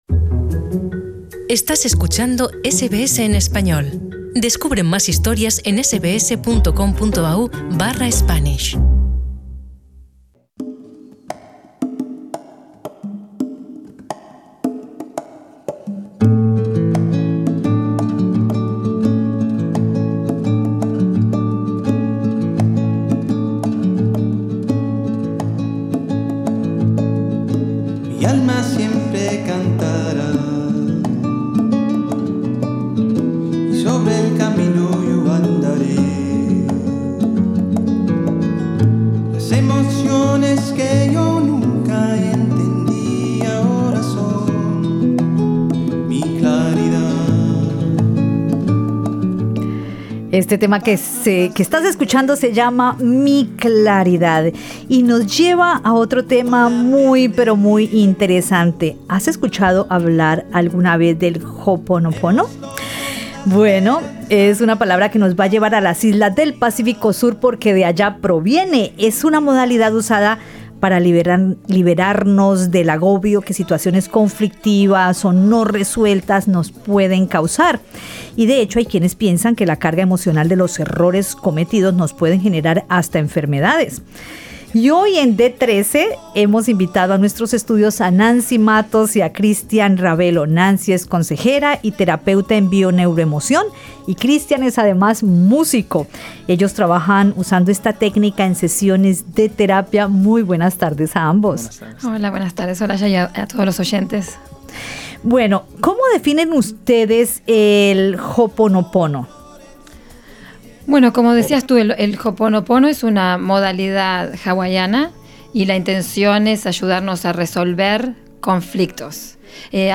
en los estudios de Radio SBS